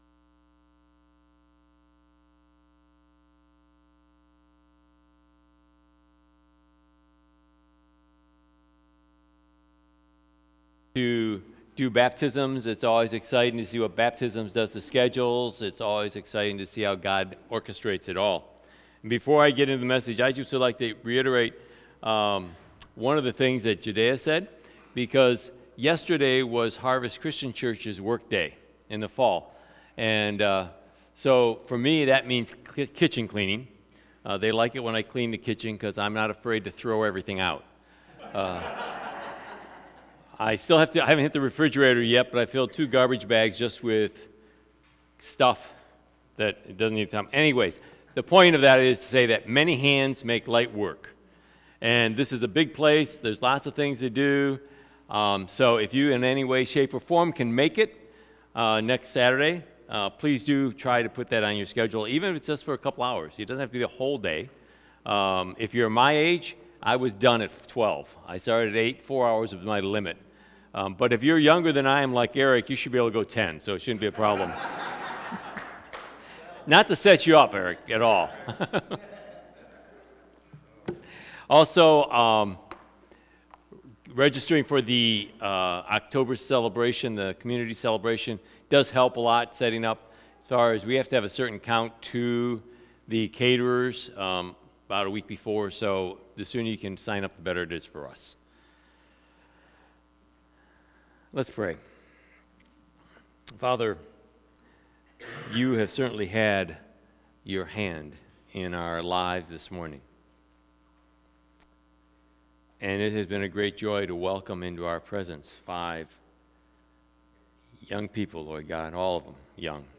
September 24 Sermon | A People For God